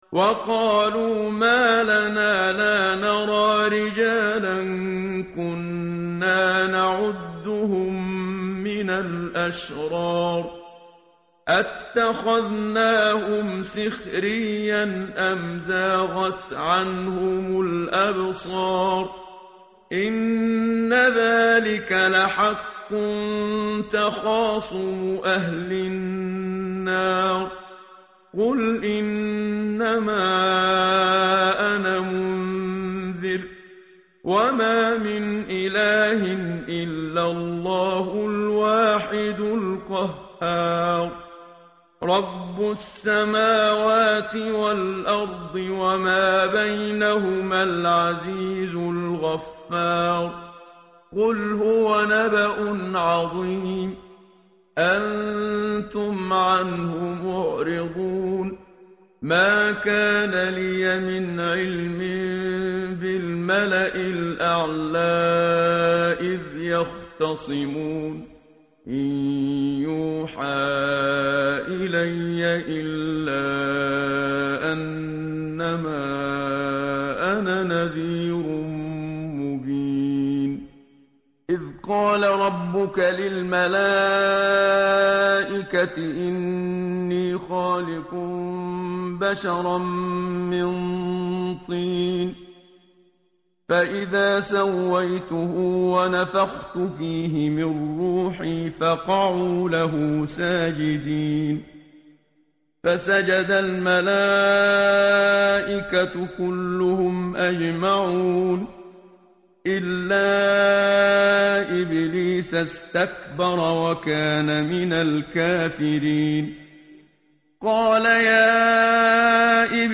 tartil_menshavi_page_457.mp3